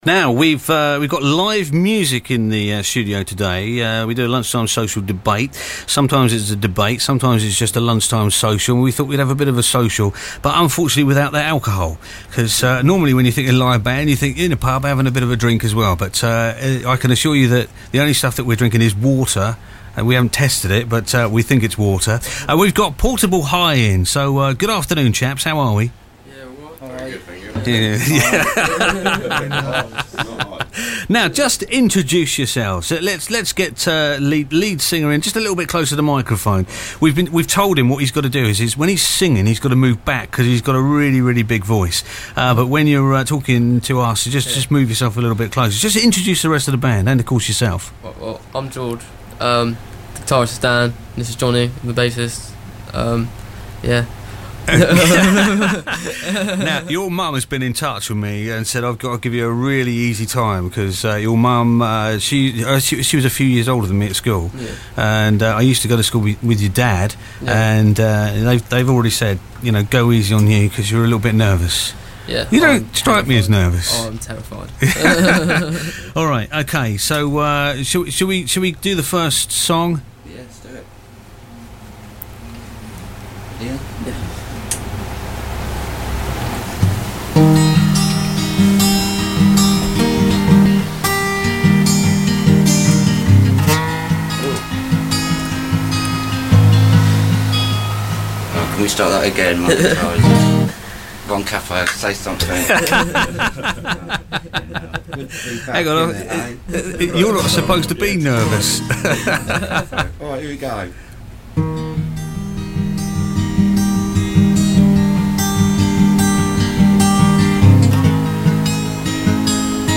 The Band Plays Some Of Their Own personal songs and a Few Covers.